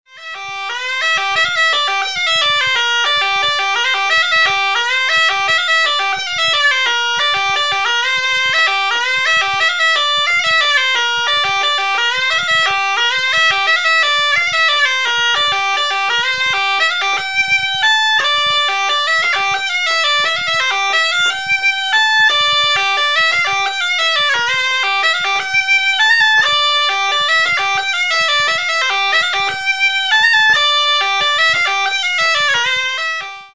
Cabrettes et Cabrettaïres le site Internet officiel de l'association de musique traditionnelle auvergnate
Les bourrées